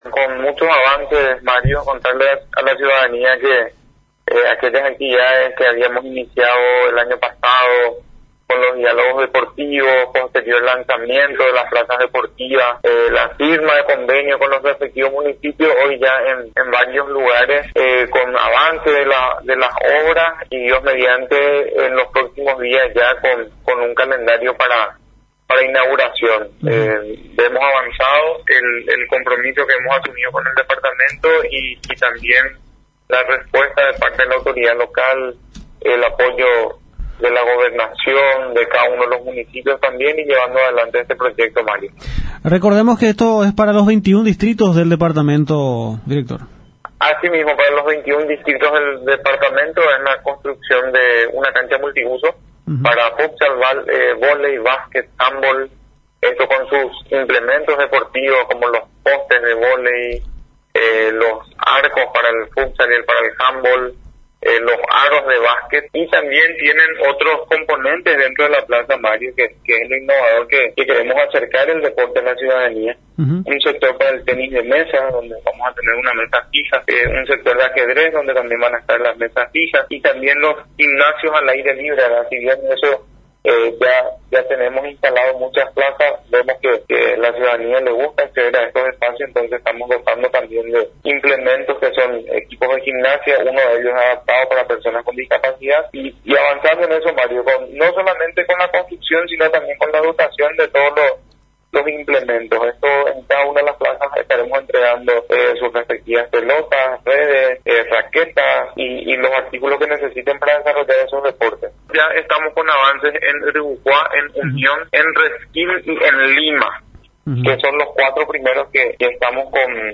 El Director General de la Secretaría Nacional de Deportes, Ricardo Deggeller, en contacto con Radio Nacional, informó a toda la población sampedrana acerca de las labores que se encuentran desarrollando, en coordinación con las autoridades departamentales y municipales de San Pedro, para la construcción de las futuras plazas deportivas para todos los municipios del segundo departamento.